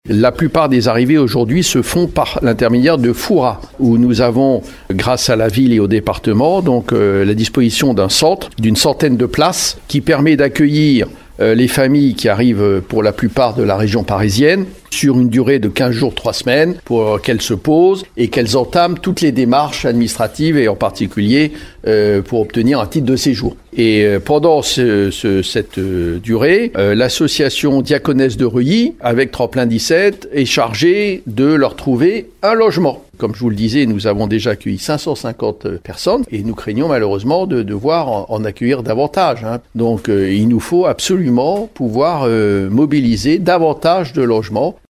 On écoute le représentant de l’Etat qui relance un appel aux collectivités afin de trouver des solutions d’hébergement de longue durée :